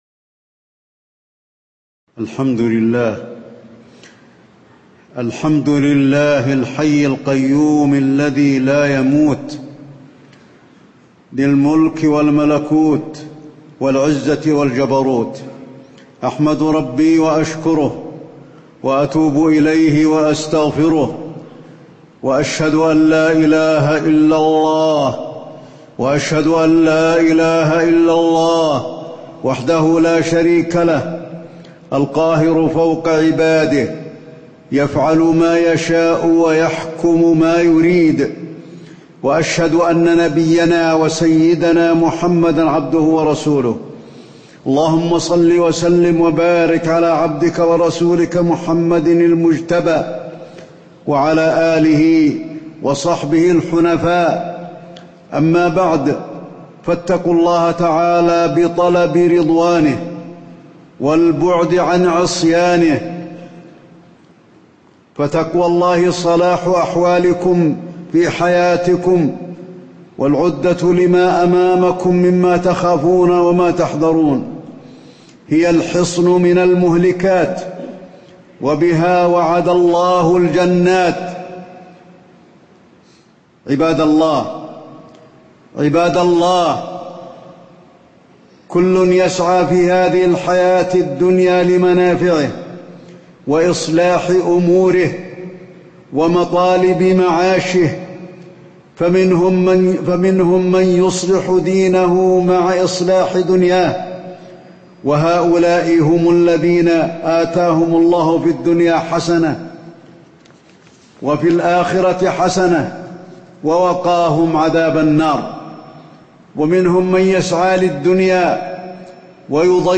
تاريخ النشر ٢٠ محرم ١٤٣٨ هـ المكان: المسجد النبوي الشيخ: فضيلة الشيخ د. علي بن عبدالرحمن الحذيفي فضيلة الشيخ د. علي بن عبدالرحمن الحذيفي الاستعداد لمفارقة الحياة